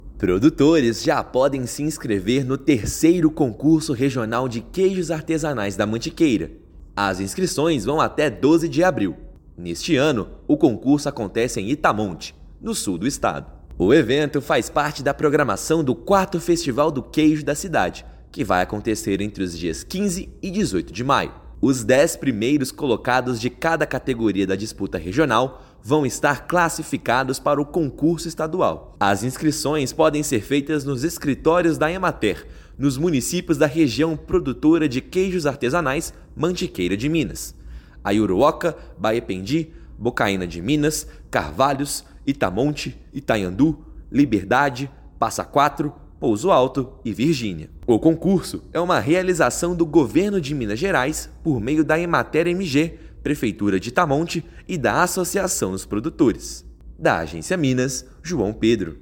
Inscrições vão até 12/4; melhores queijos garantem vaga na etapa estadual. Ouça matéria de rádio.